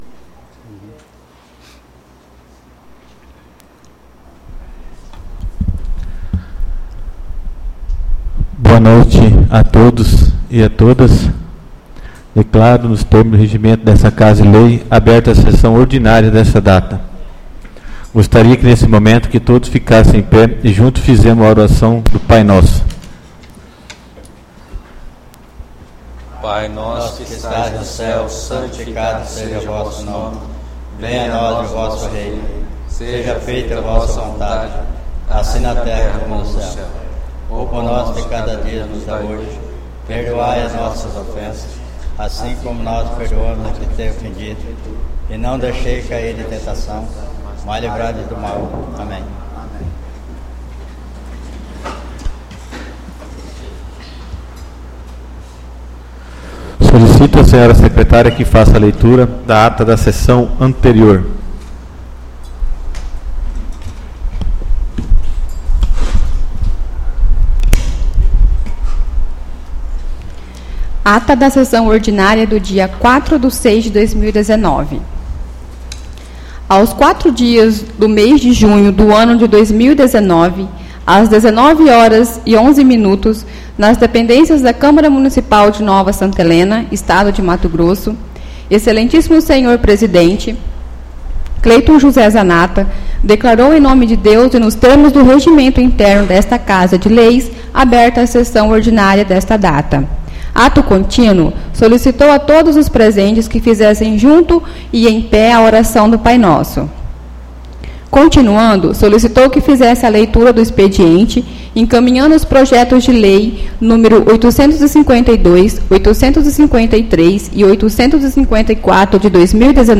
Áudio Sessão Ordinária 11/06/2019